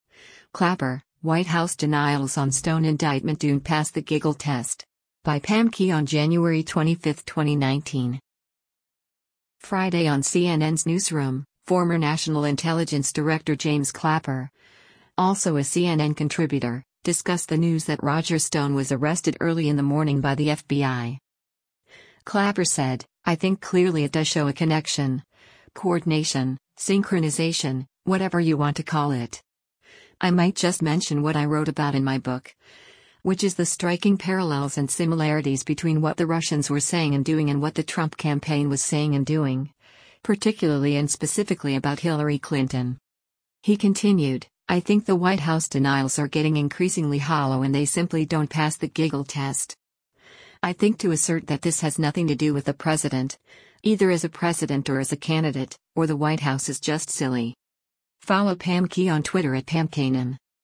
Friday on CNN’s “Newsroom,” former National Intelligence Director James Clapper, also a CNN contributor, discussed the news that Roger Stone was arrested early in the morning by the FBI.